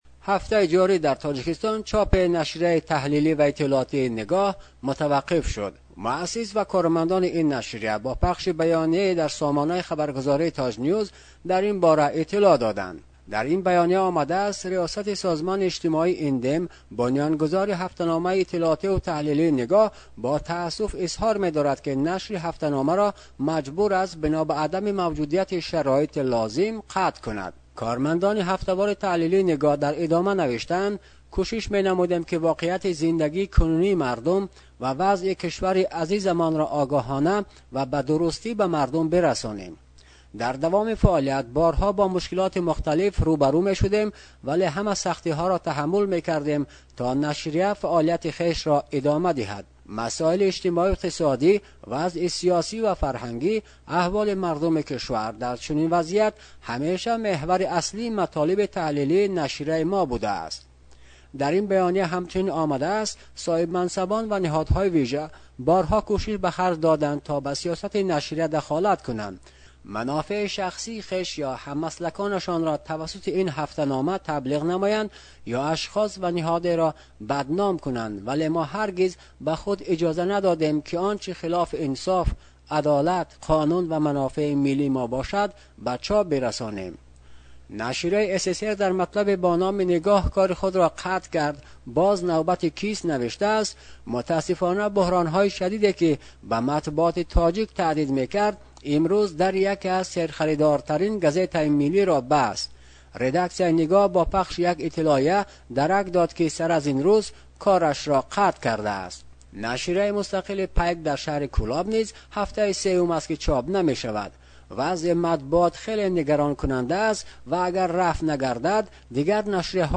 Баррасии матбуоти чопи Душанбе